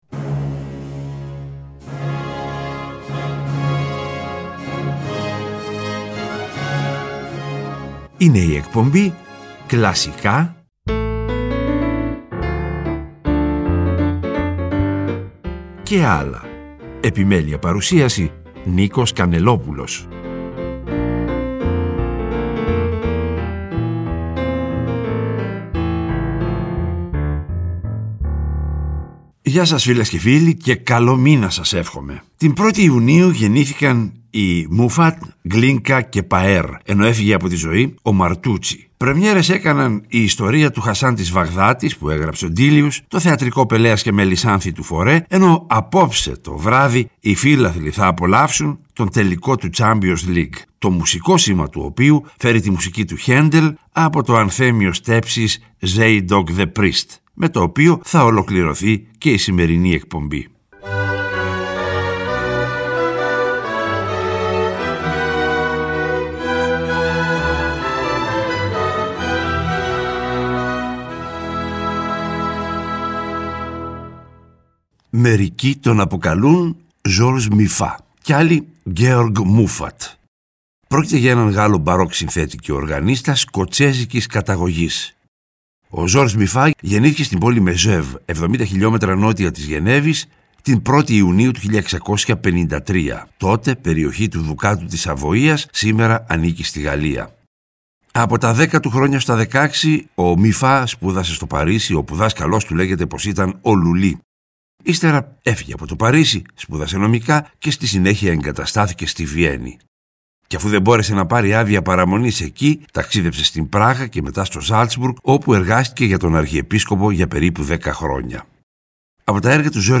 Αυτό το βράδυ οι φίλαθλοι θα απολαύσουν τον τελικό του Champions League, το σήμα του οποίου φέρει τη μουσική του Χαίντελ, από το ανθέμιο στέψης “Zadok the Priest” (1727), με το οποίο ολοκληρώνεται η εκπομπή.
Και, προς το τέλος κάθε εκπομπής, θα ακούγονται τα… «άλλα» μουσικά είδη, όπω ς μιούζικαλ, μουσική του κινηματογράφου -κατά προτίμηση σε συμφωνική μορφή- διασκευές και συγκριτικά ακούσματα.